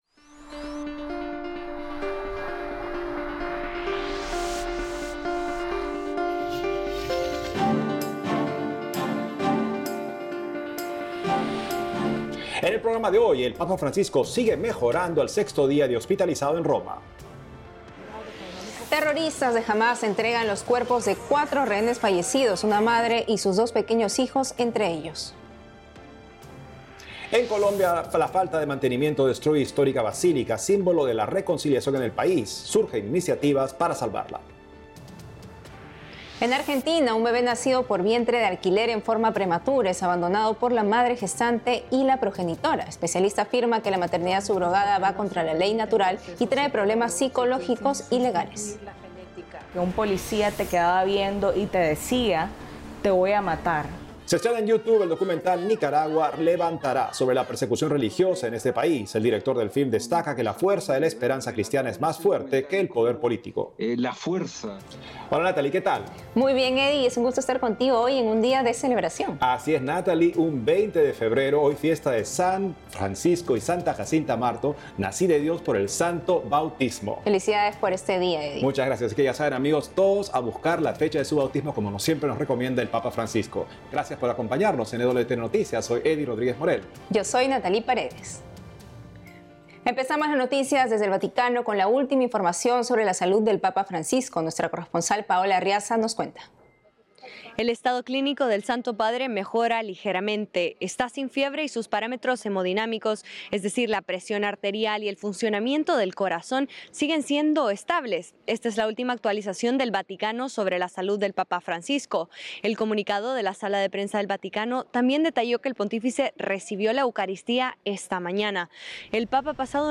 Noticias católicas | Programa completo.
Noticiero diario producido exclusivamente para EWTN por la agencia ACI Prensa de Perú. Este programa informativo de media hora de duración se emite los sábados (con repeticiones durante la semana) y aborda noticias católicas del mundo y las actividades de Su Santidad Francisco; incluye también reportajes a destacados católicos de América del Sur y América Central.